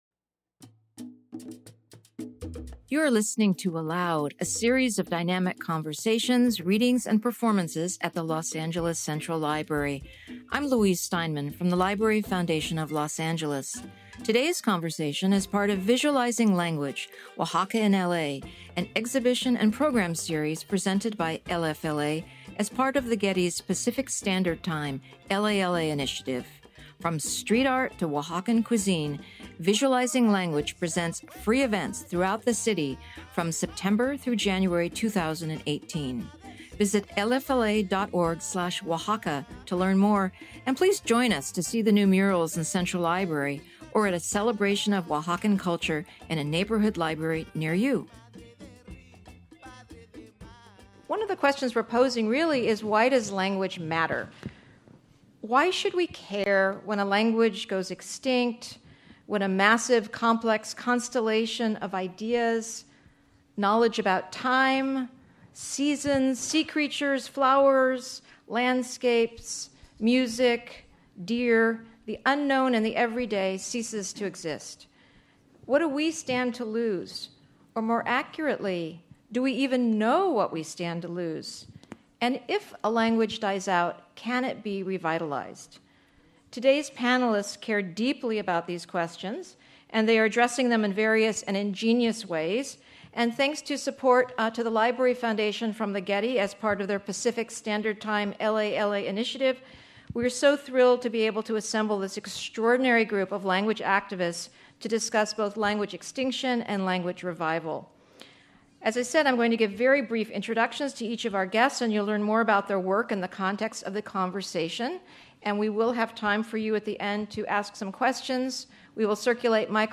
Join ALOUD for a freewheeling conversation among language activists working to reclaim indigenous languages in California and Mexico.